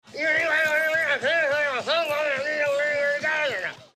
Play, download and share mumble original sound button!!!!
mumble.mp3